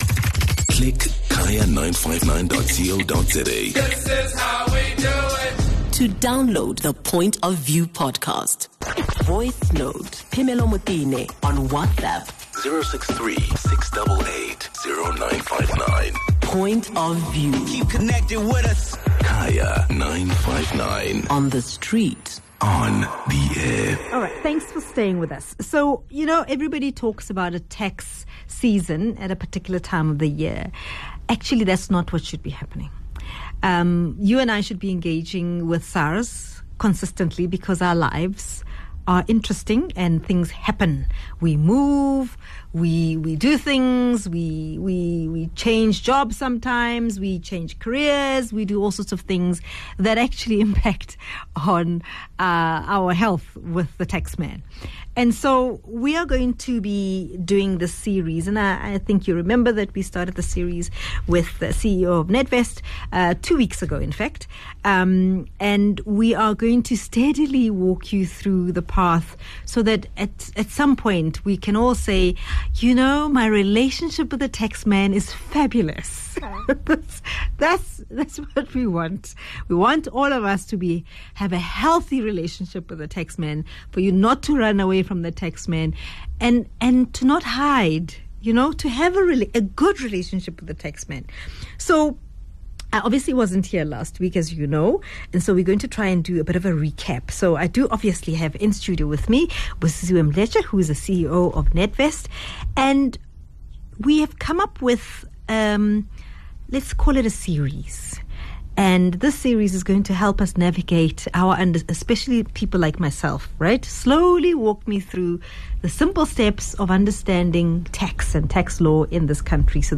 13 May DISCUSSION: Paying Tax and living abroad